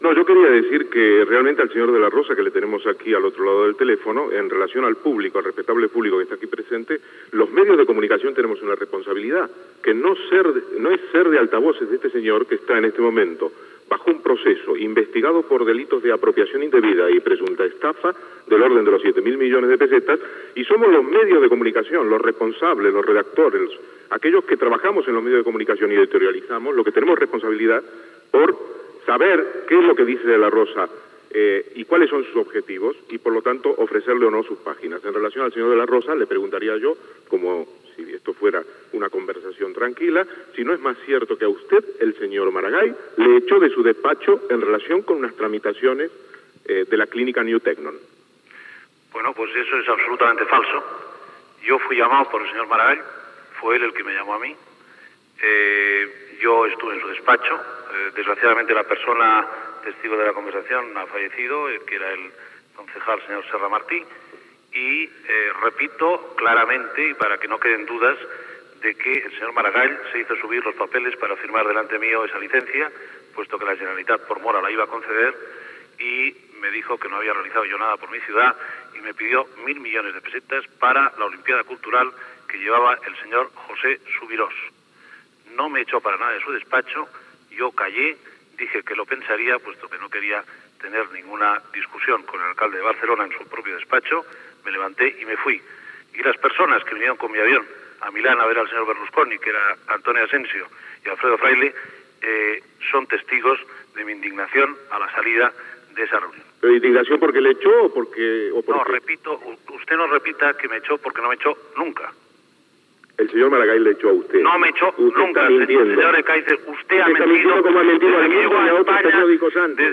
Preguntes dels periodistes Ernesto Ekaizer, Pedro J. Ramírez, Pepe Oneto i José Luis Martin Prieto a l'empresari Javier de la Rosa
Info-entreteniment